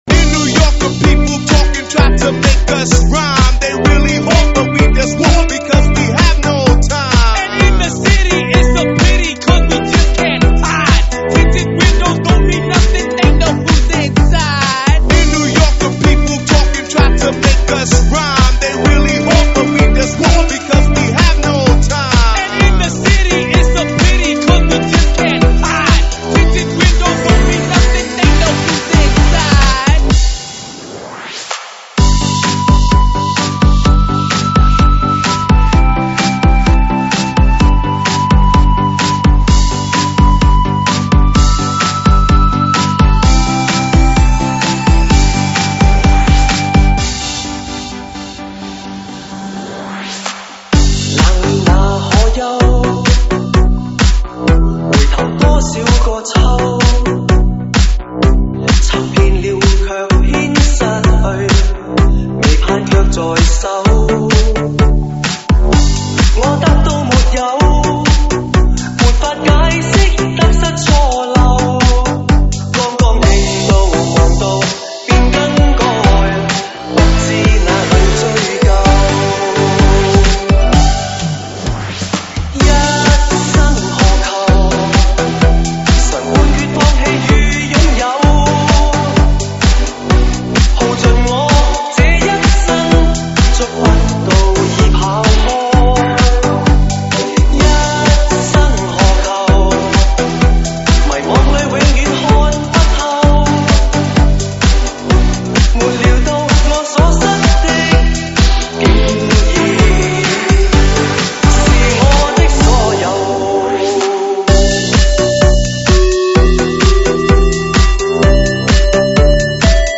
粤语经典